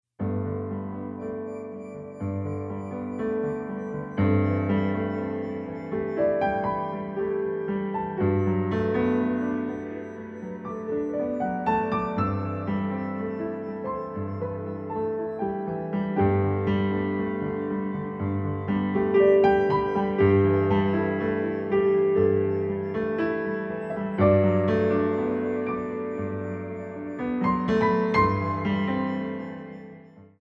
two beats